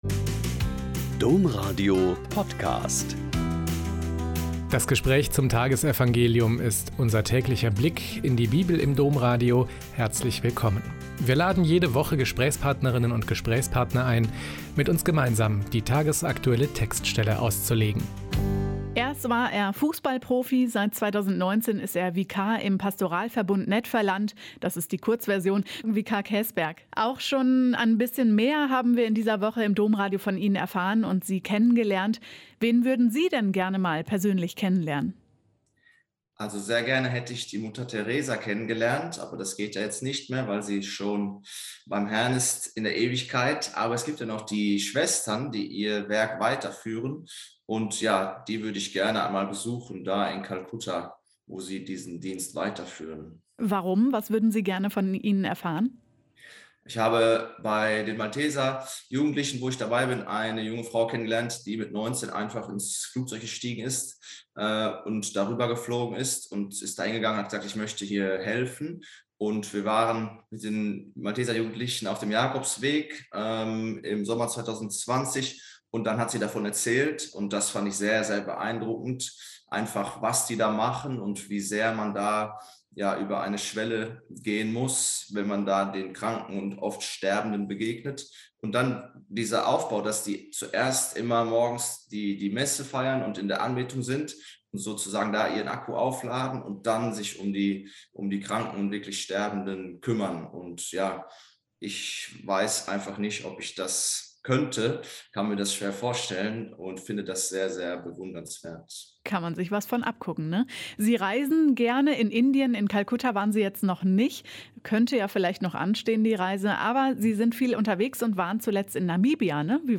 Mk 16,9-15 - Gespräch